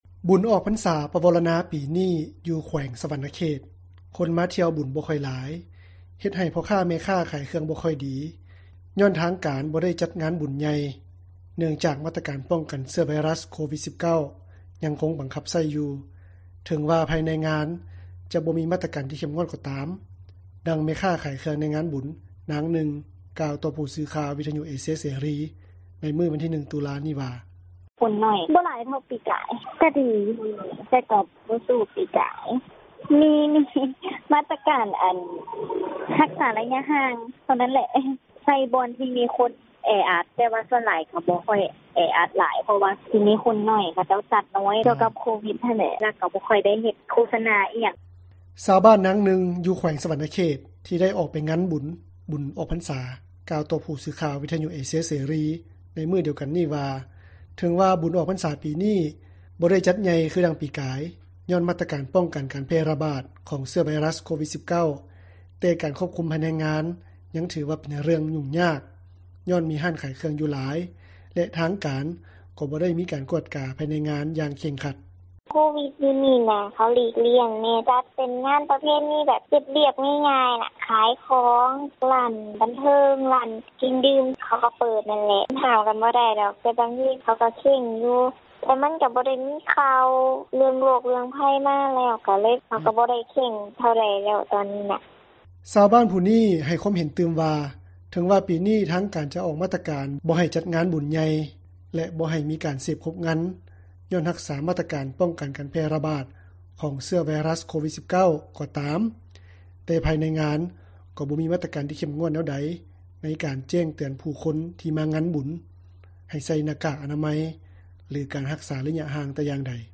ດັ່ງແມ່ຄ້າຂາຍເຄື່ອງໃນງານບຸນ ນາງນຶ່ງ ກ່່າວຕໍ່ຜູ້ສື່ຂ່າວ ວິທຍຸເອເຊັຽເສຣີ ໃນມື້ວັນທີ 01 ຕຸລາ ນີ້ວ່າ: